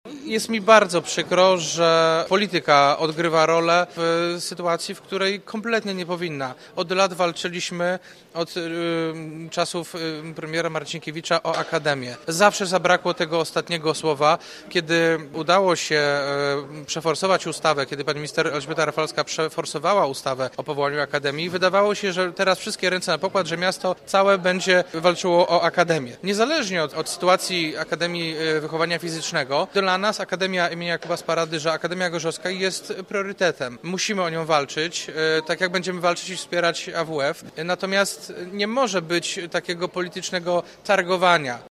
Po pierwsze, aby uczelnię wesprzeć, a po drugie – by zaoszczędzić pieniądze, bo w tej chwili za wynajem obiektu płaci dla uczelni 600 tysięcy złotych rocznie. Przypomnijmy, co po głosowaniu mówił nam prezydent Jacek Wójcicki.